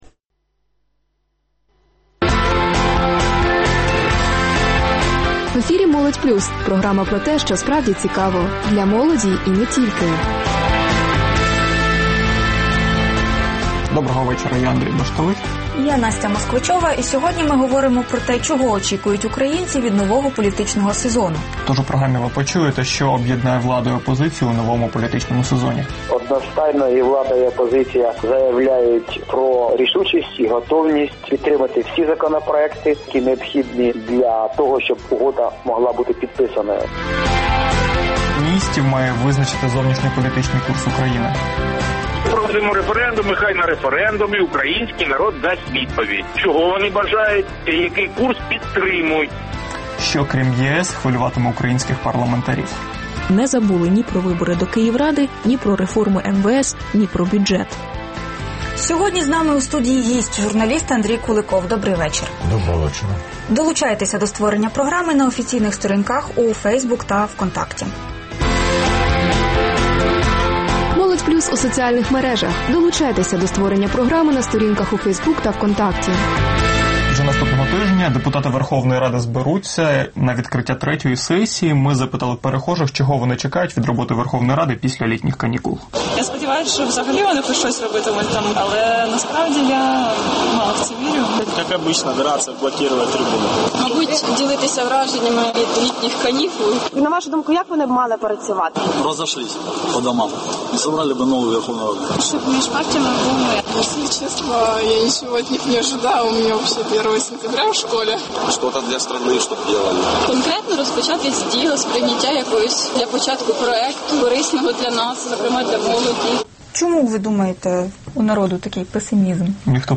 Журналіст Андрій Куликов в ефірі «Молодь Плюс» розповідає, чого варто сподіватися від нового політичного сезону та про інтерв'ю з Януковичем.